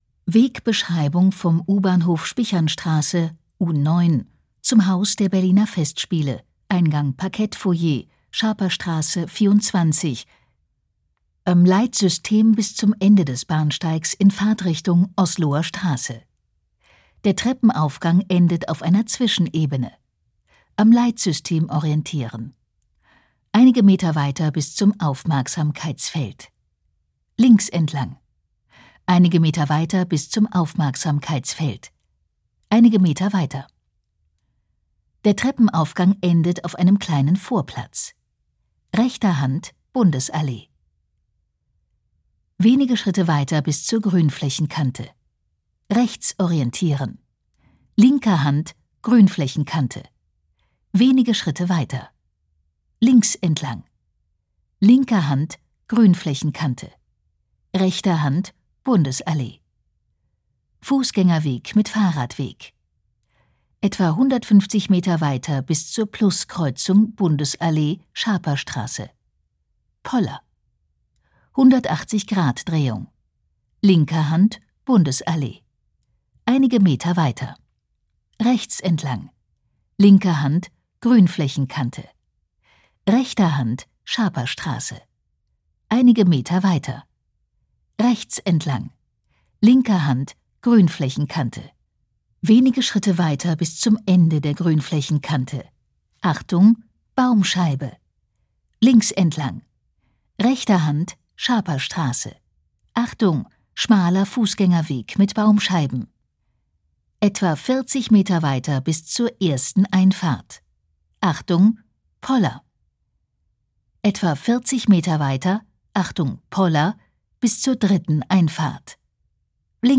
Audio-Wegbeschreibungen